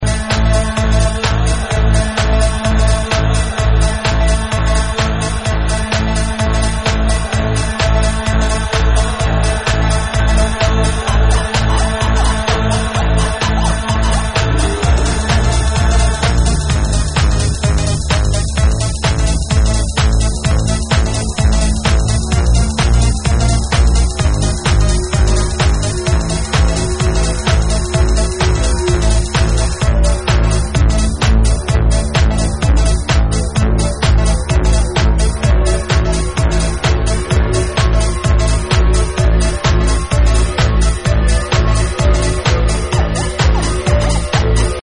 monstrously minimal remix
'in yer face' workout